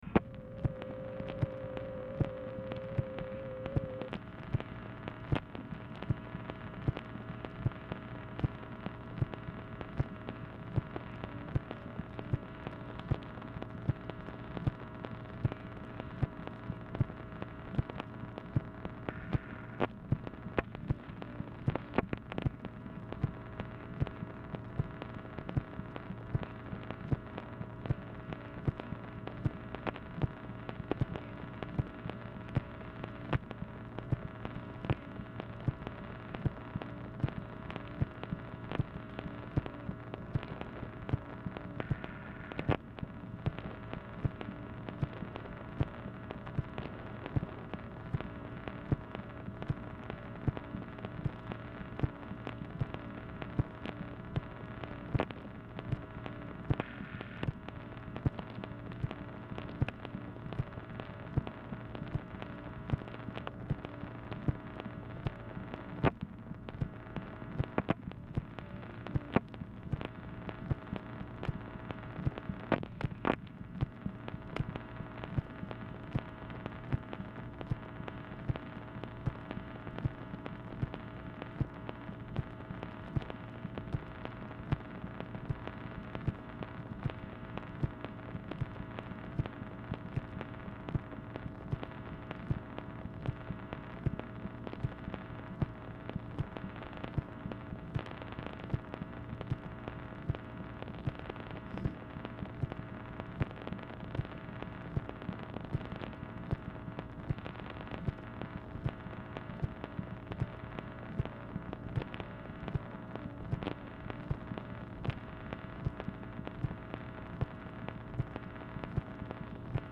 Telephone conversation # 7859, sound recording, MACHINE NOISE, 5/31/1965, time unknown | Discover LBJ
CONCURRENT RECORDING OF REF #7857
Format Dictation belt
Specific Item Type Telephone conversation